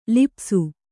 ♪ lipsu